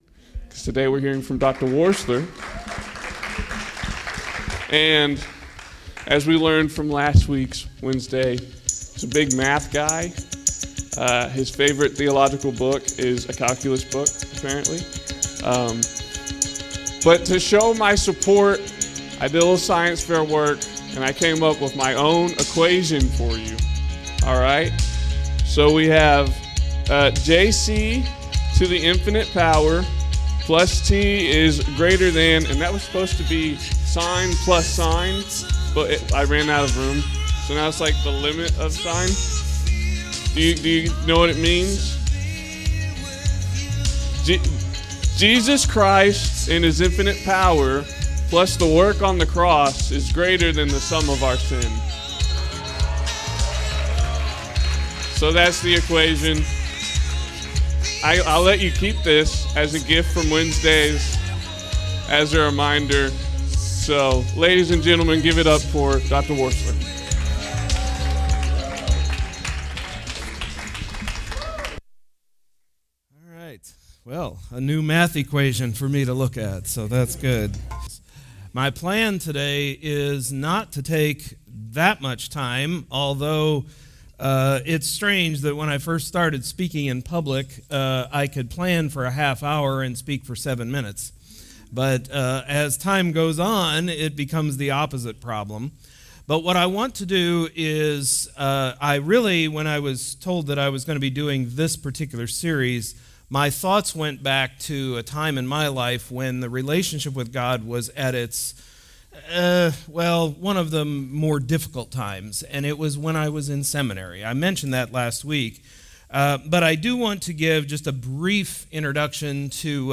Wednesdays Chapel